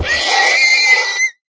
sounds / mob / horse / death.ogg
death.ogg